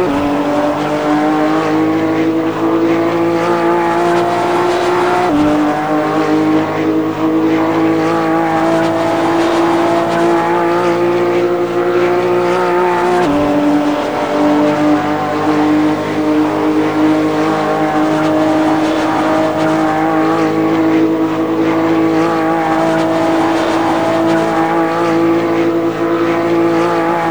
Index of /server/sound/vehicles/vcars/porsche911carrera